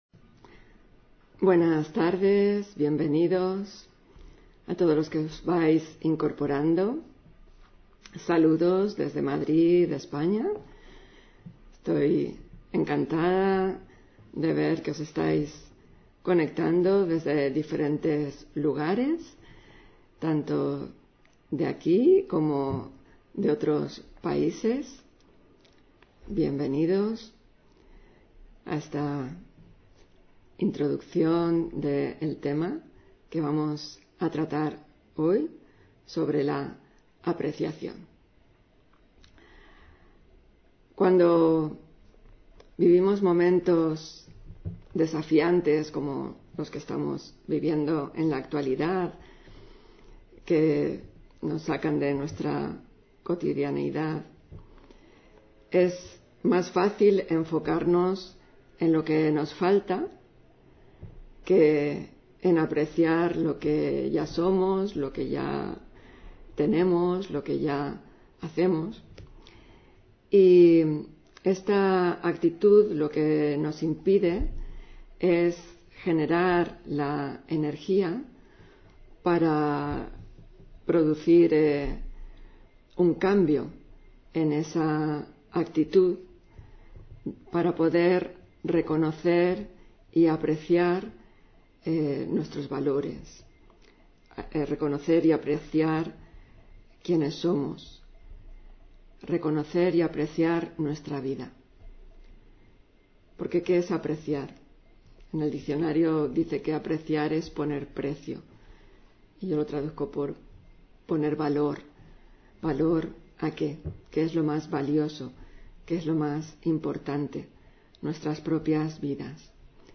Meditación Raja Yoga: Apreciación (22 Mayo 2020) On-line desde Madrid